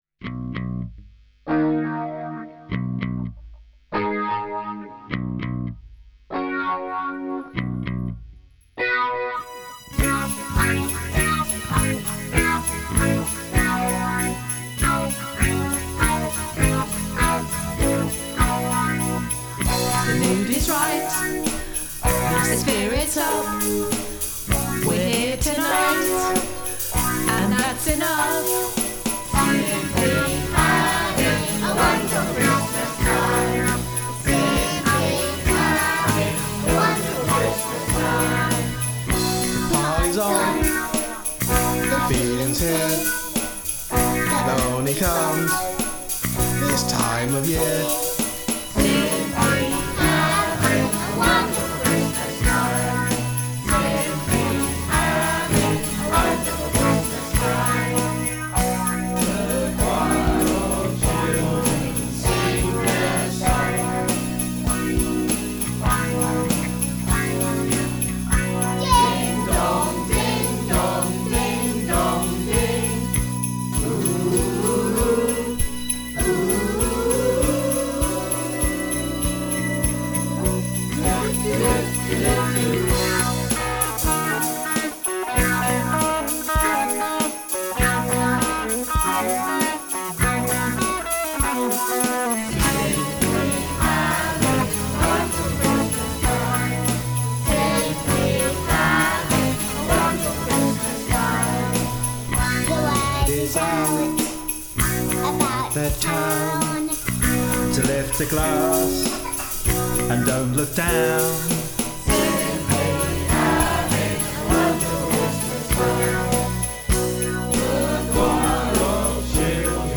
Bass
Guitar/Keyboards
Drums/Guitar